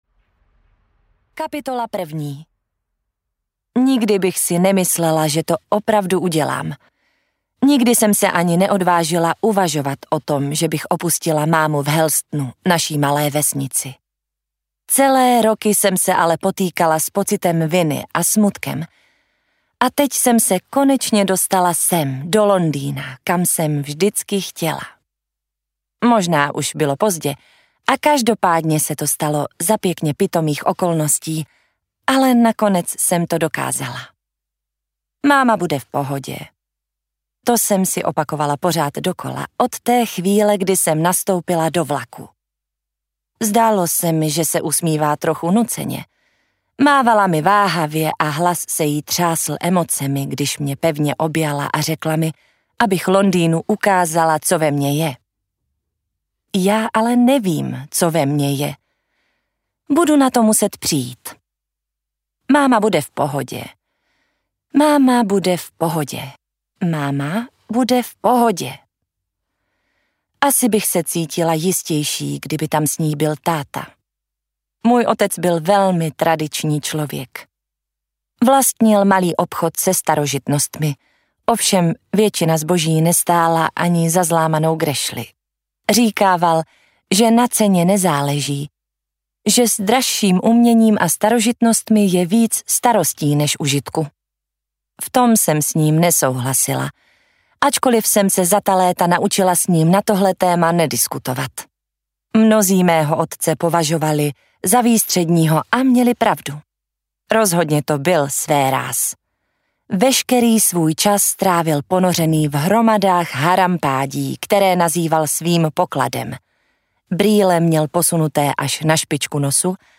Nemravné lži audiokniha
Ukázka z knihy